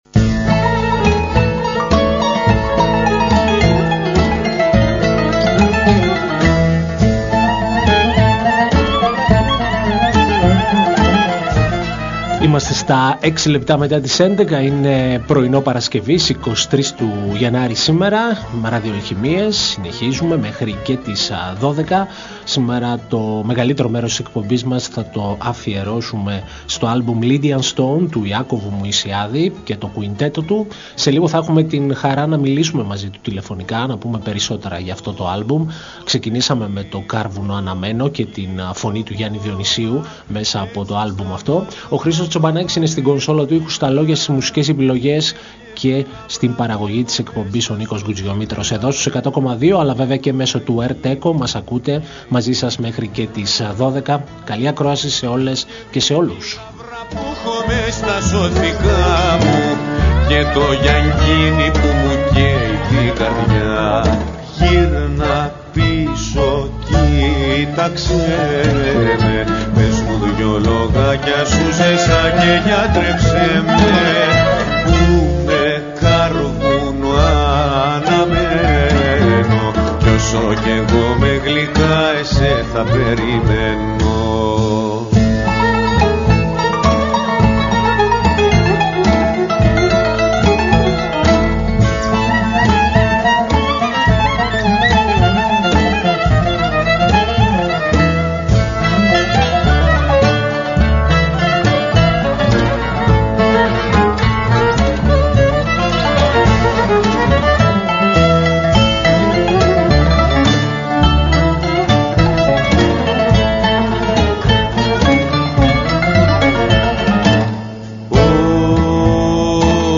Μια εκπομπή μουσικής και λόγου διανθισμένη με επιλογές από την ελληνική δισκογραφία.
Συνεντευξη